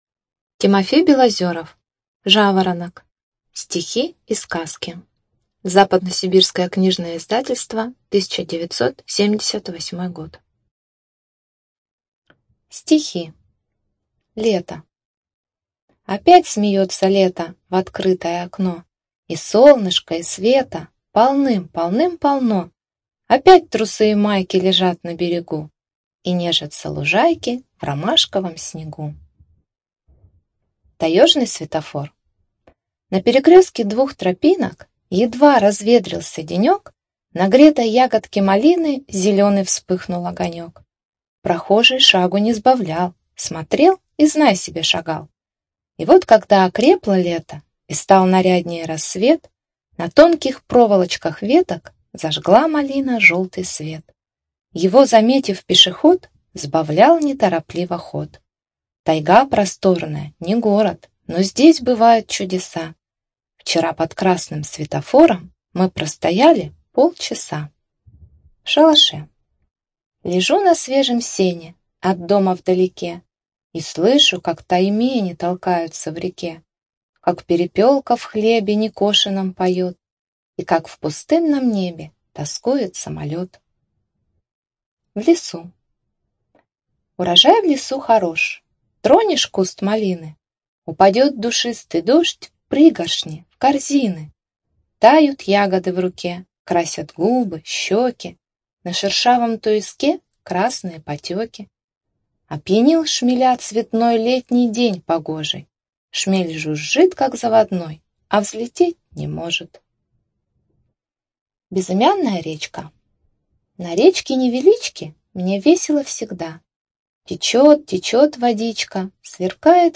Студия звукозаписиОмская областная библиотека для слепых